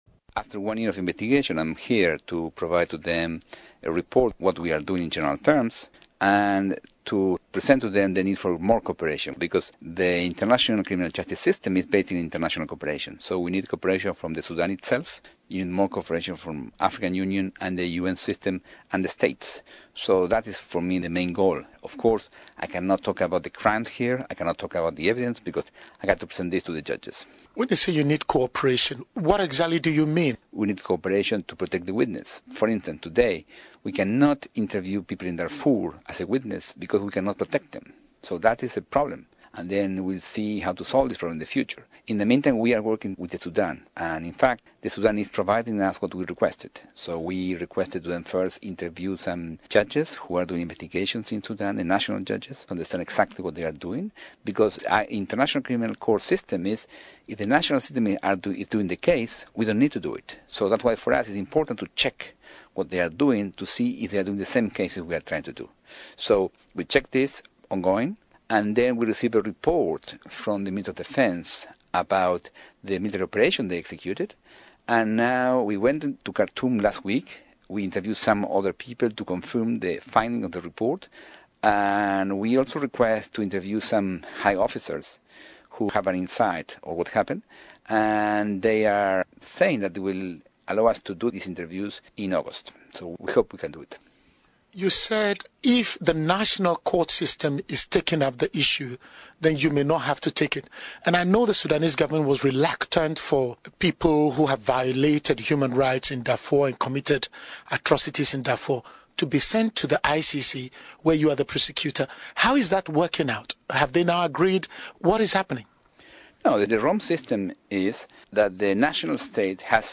In unserem Audio-Angebot hören Sie das Interview in englischer Originalsprache. Quelle: UN-Radio, New York.